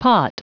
Prononciation du mot pot en anglais (fichier audio)
Prononciation du mot : pot